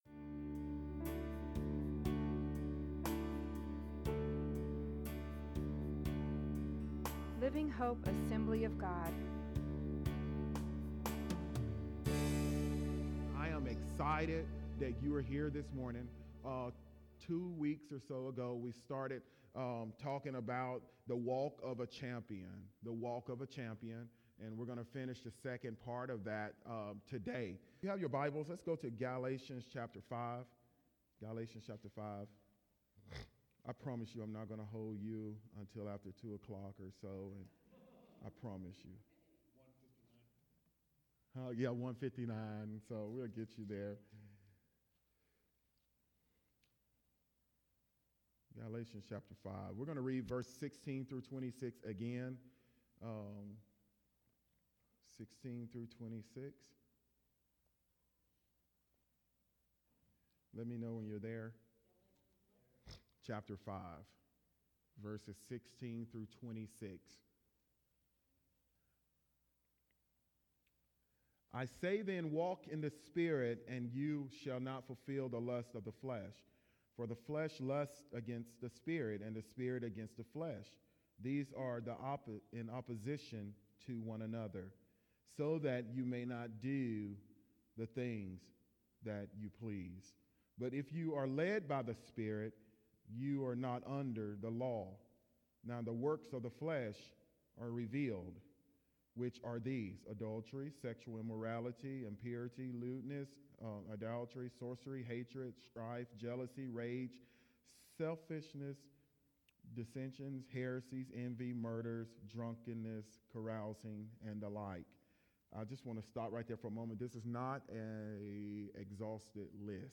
Sermon from January 1, 2017 This is the conclusion of the series "The Walk of a Champion" Key Scripture: Galatians 5:16-26